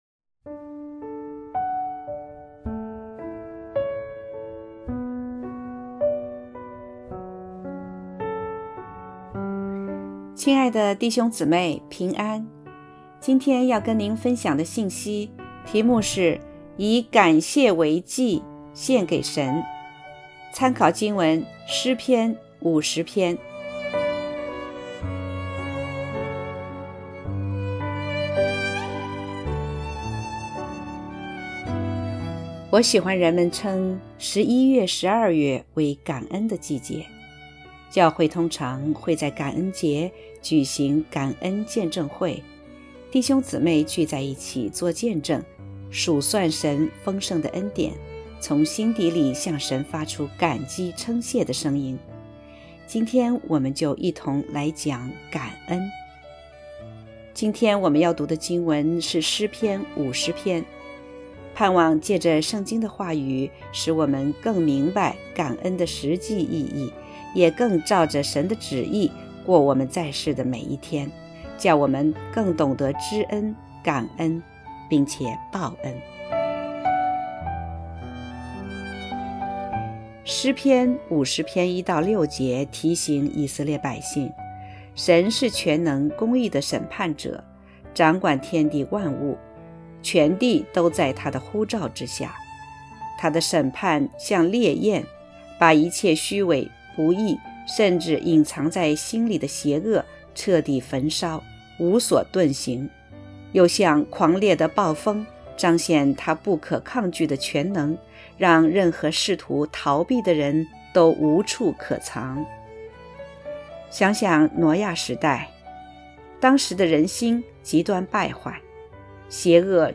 （合成）Y以感谢为祭献给神-.mp3